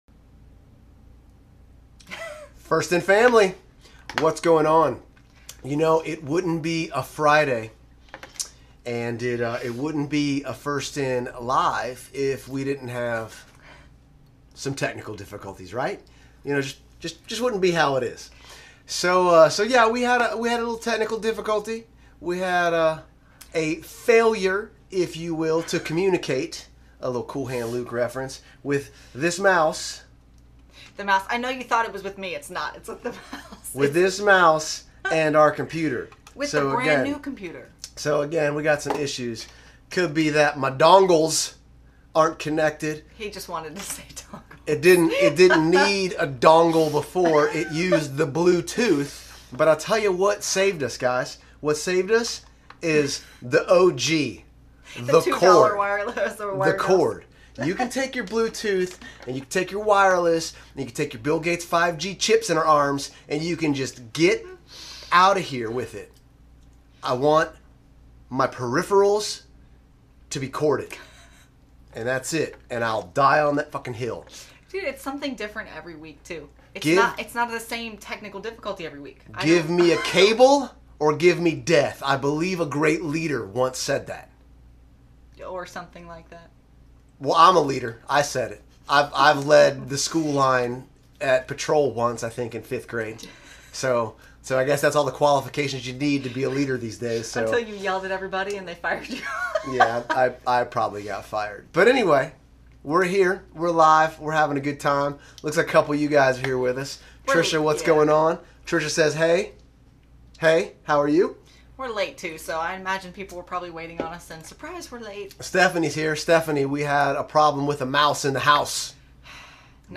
FIN LIVE Q+A: 5/28/21